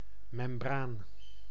Ääntäminen
IPA: /mɛmbran/